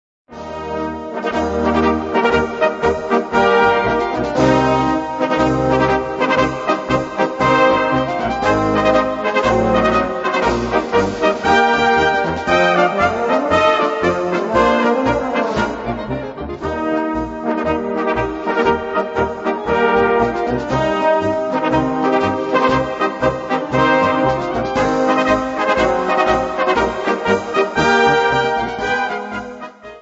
Gattung: Konzertmarsch
A4 Besetzung: Blasorchester PDF